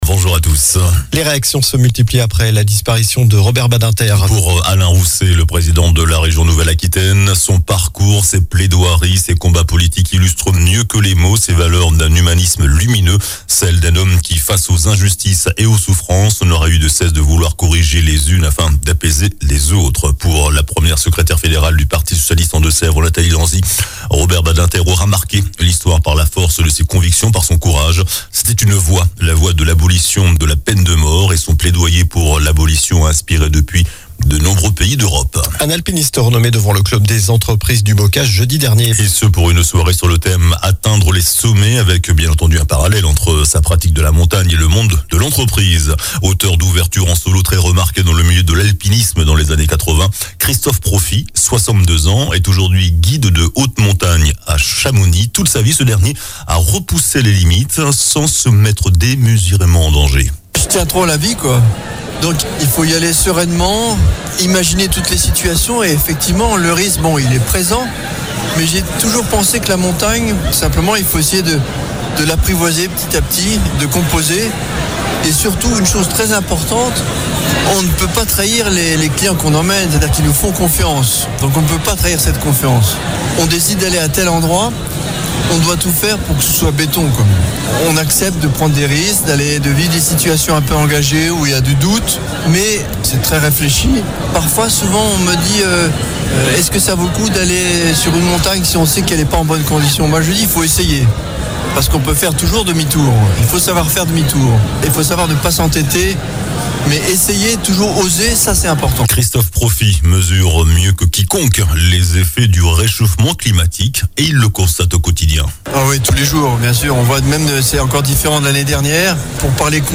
JOURNAL DU SAMEDI 10 FEVRIER